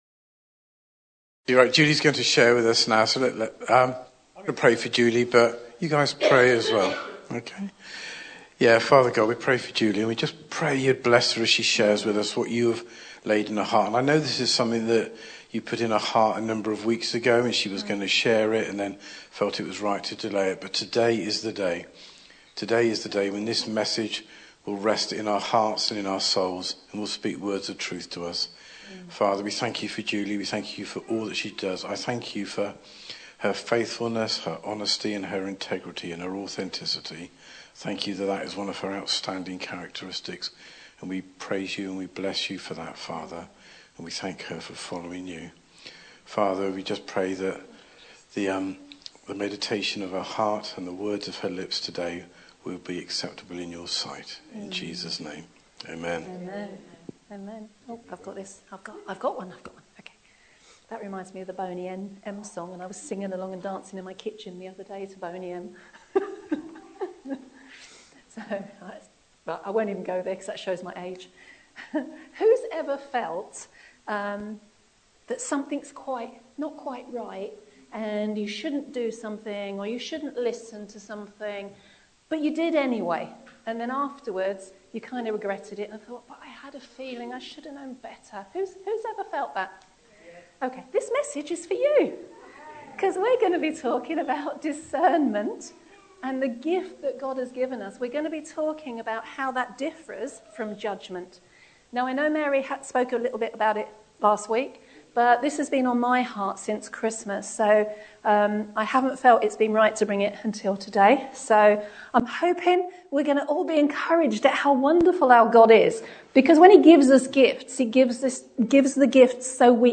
The latest talks from River Church in Slough, Maidenhead, Marlow and Englefield Green